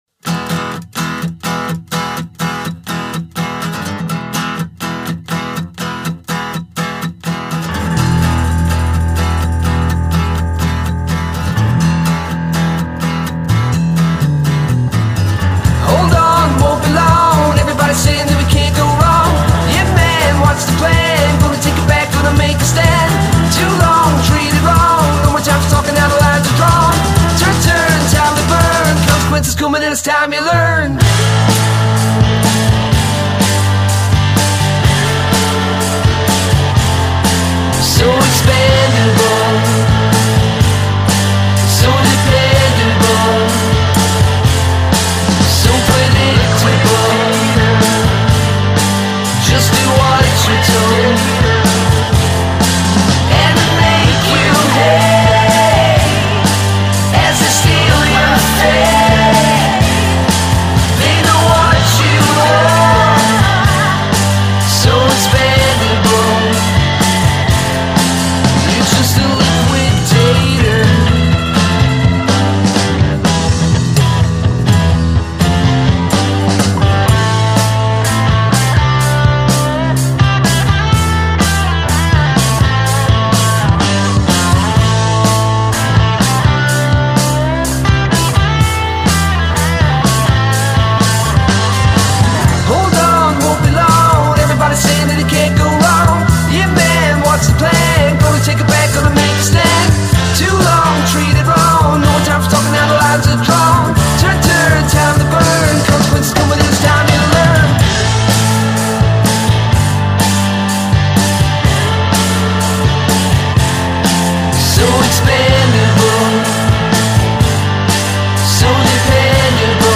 6 piece psychedelic rock band